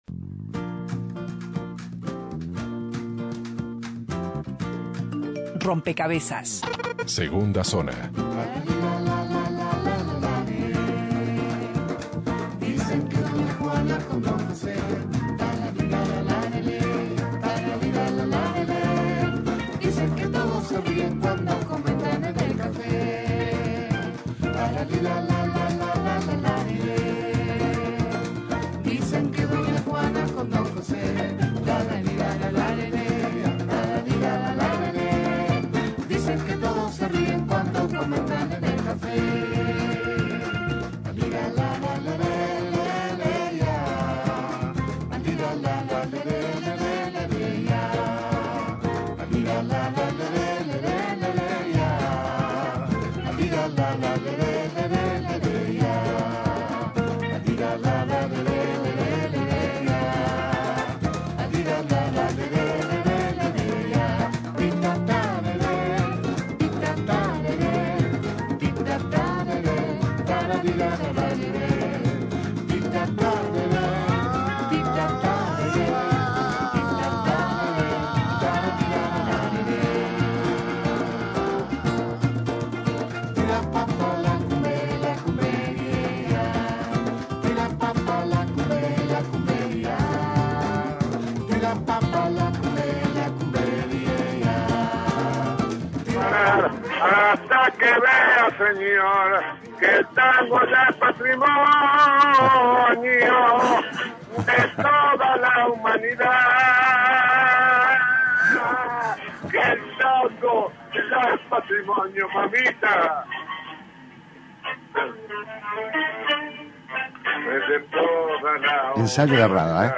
Rada interrumpió un ensayo para conversar con Rompkbzas sobre su último show que ofrecerá mañana, miércoles, a la hora 21 en la sala Adela Reta del Auditorio Nacional del Sodre.